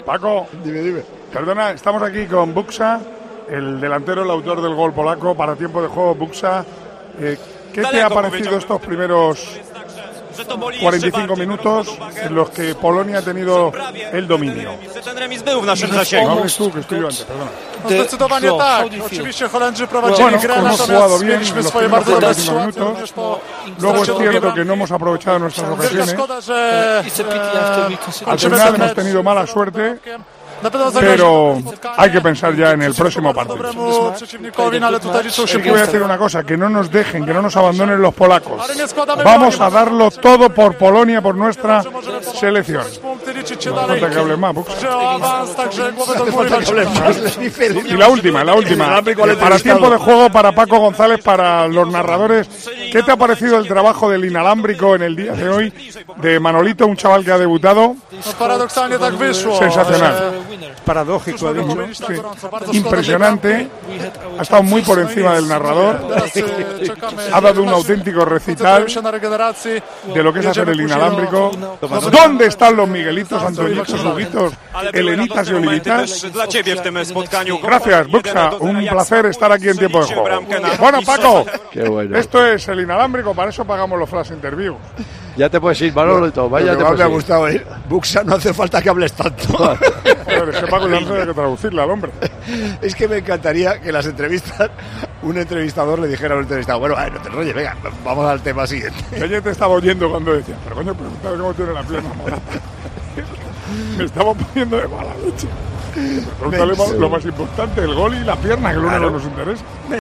Manolo Lama tuvo un papel particular en el encuentro de Hamburgo al ser inalámbrico del choque dejando varios momentos que desataron las carcajadas del equipo de Tiempo de Juego: "Paco, van a cambiar muchas cosas". Una actuación que acabó con la 'entrevista' a Buksa, el goleador polaco y que puedes escuchar en el siguiente audio.
Manolo Lama pone el broche final a su actuación como inalámbrico entrevistando a Buksa